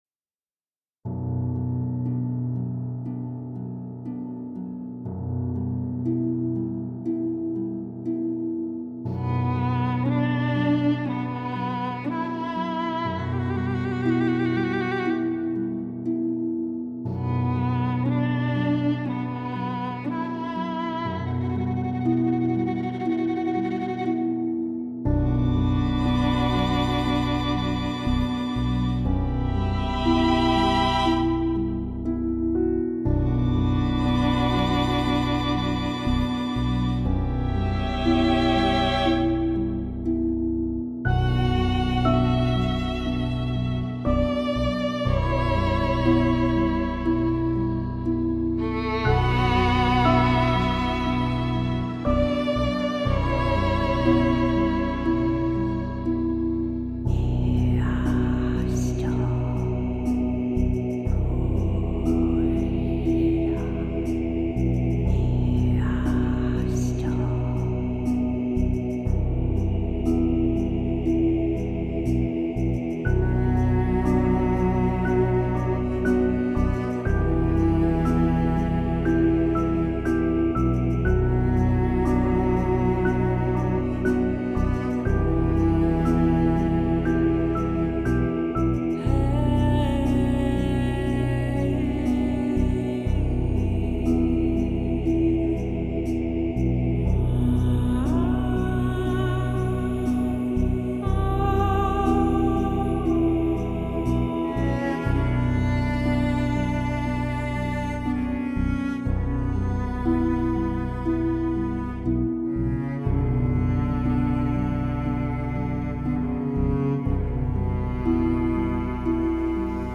KDzsuFL3tk0_goetia-dark-magic-music.mp3